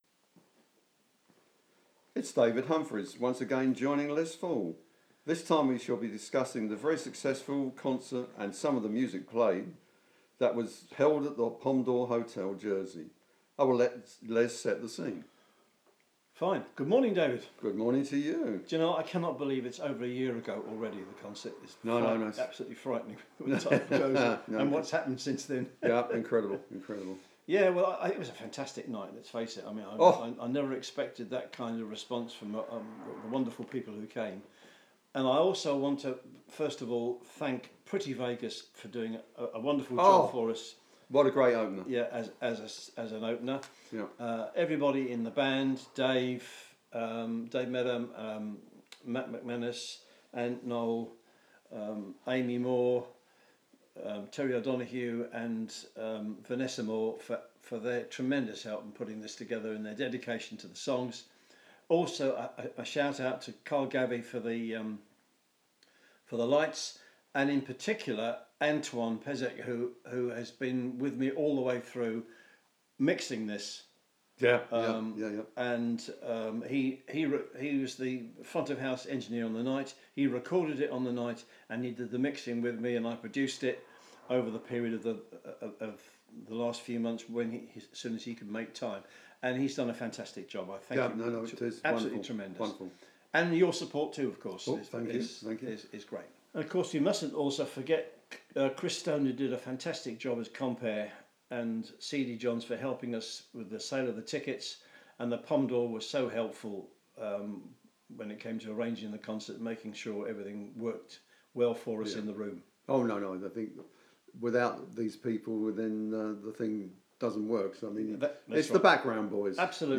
CONVERSATION
Concert-Conversation.mp3